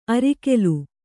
♪ arikelu